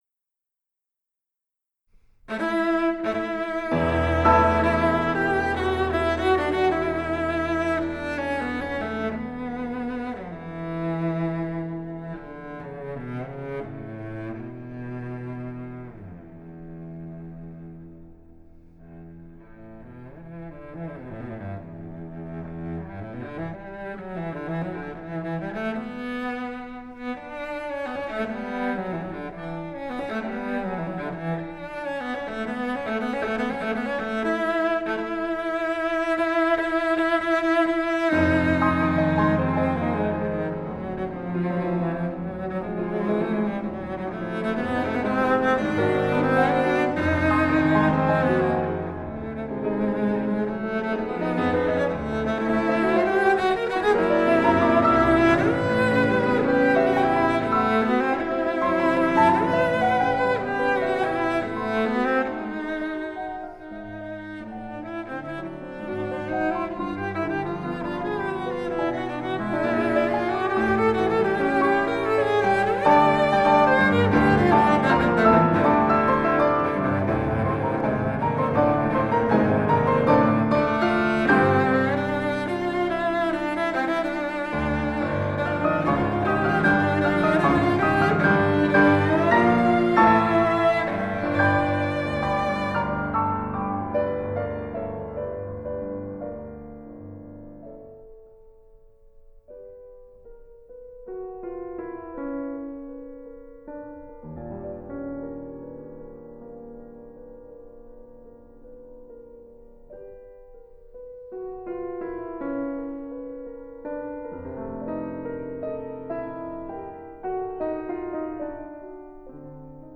★ 於加拿大魁北克Domaine Forget音樂廳錄製！